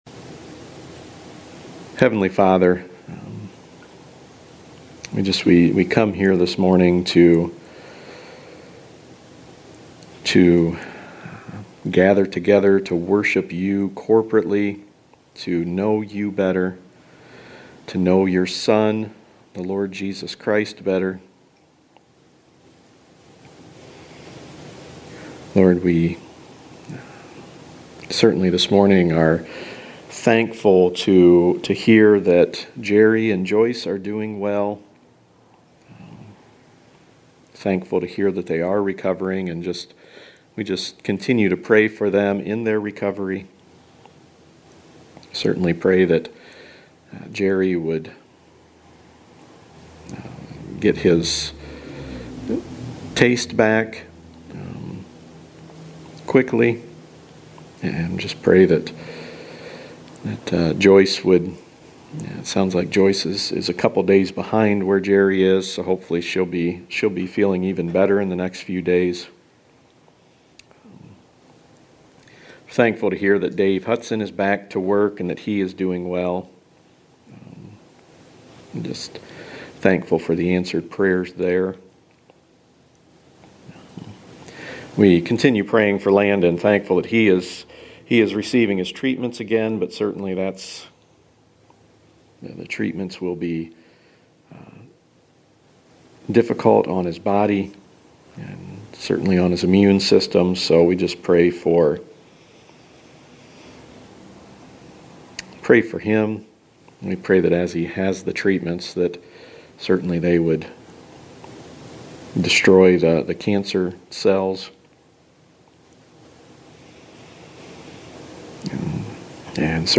The Gospel of Matthew Series (25 sermons)